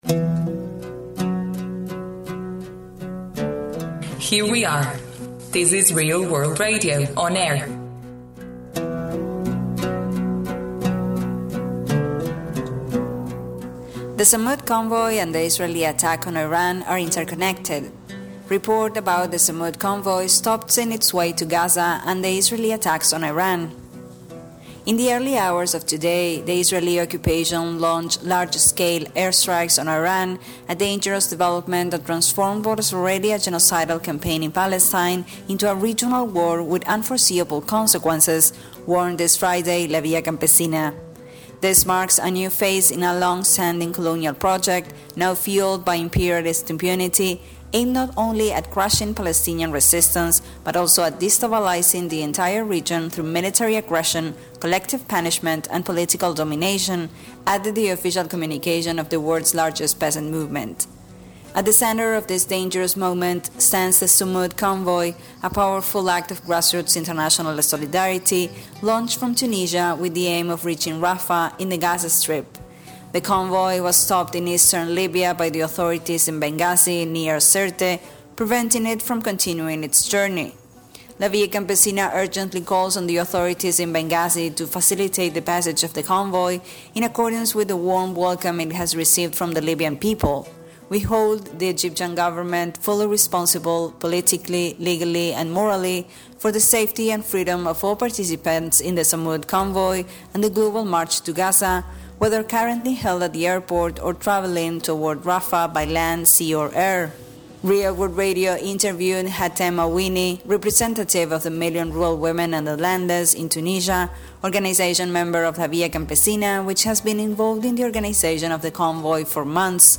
entrevista-ingles.mp3